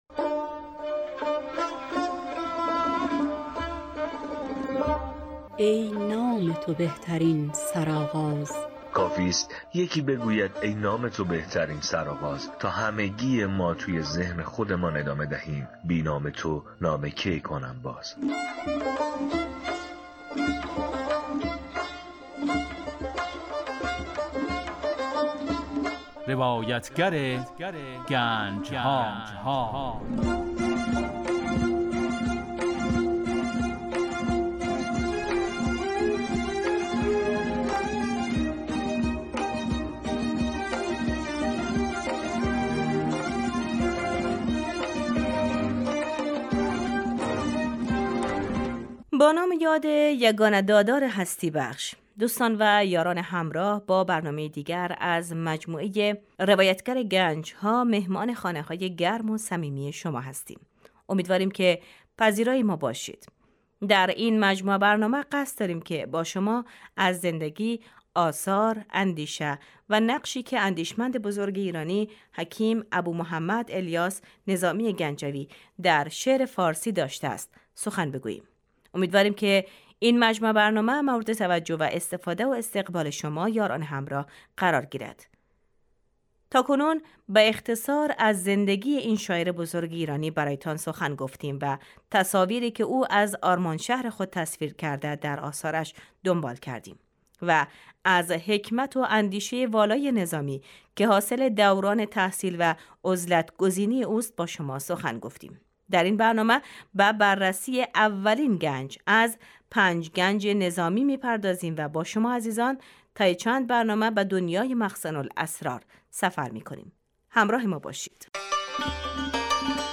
برنامه ای که سه شنبه ها از رادیو دری پخش میشود و در آن به زندگی و آثار حکیم نظامی گنجوی پرداخته میشود.